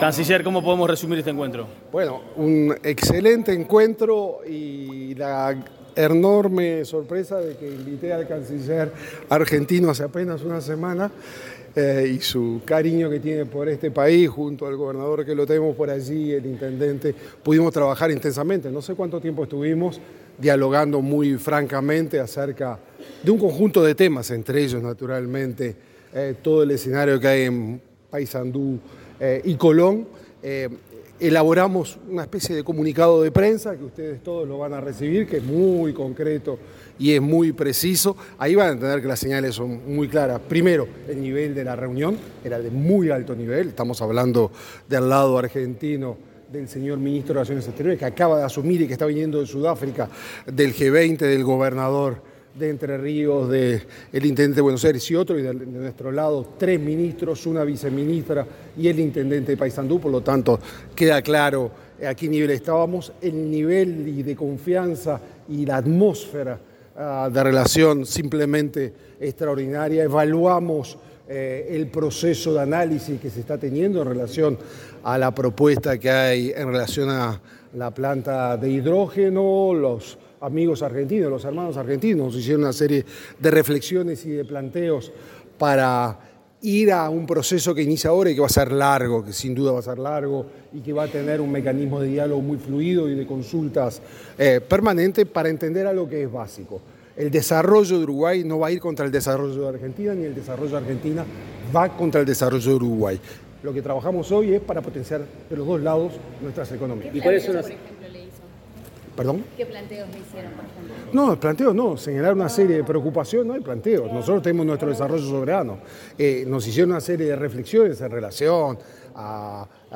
Declaraciones de los cancilleres de Uruguay y Argentina
Declaraciones de los cancilleres de Uruguay y Argentina 26/11/2025 Compartir Facebook X Copiar enlace WhatsApp LinkedIn Los cancilleres de Uruguay, Mario Lubetkin, y de Argentina, Pablo Quirno, se expresaron ante los medios de prensa, tras mantener un encuentro de alto nivel en el Palacio Santos.